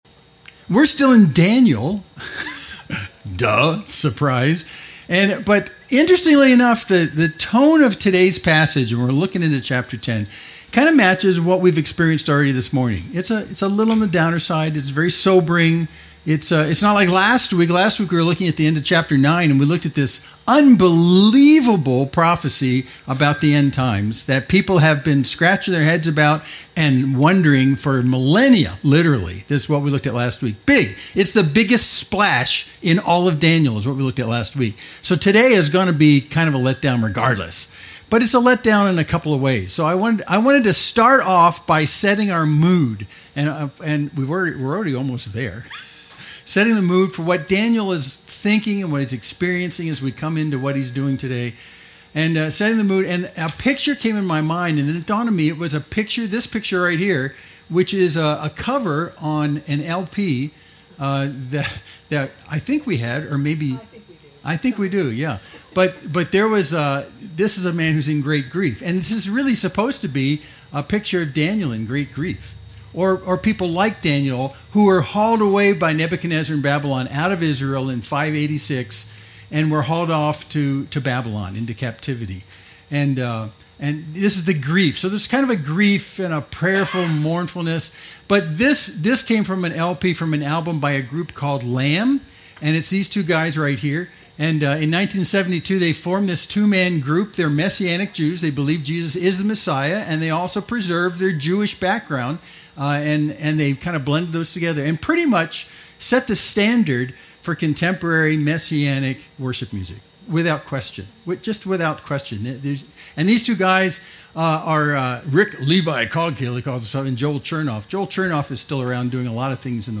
Main Street Church Sermon (17.37 - )